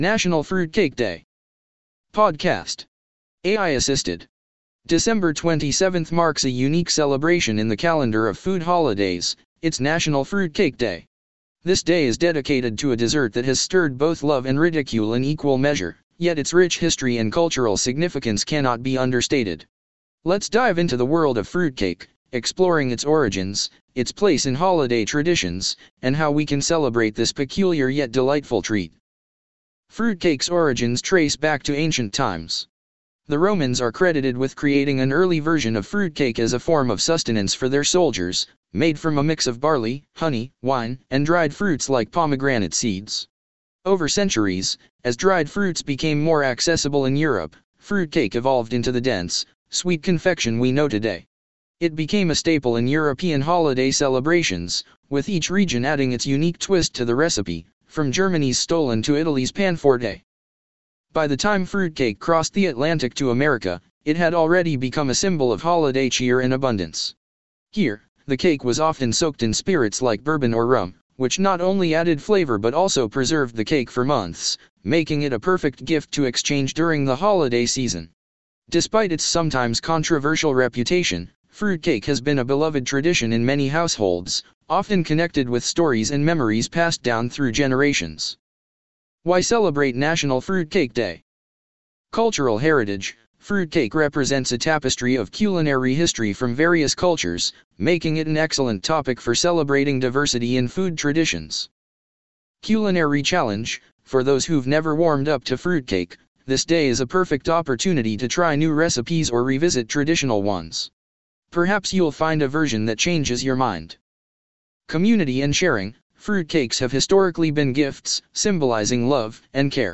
PODCAST. AI assisted.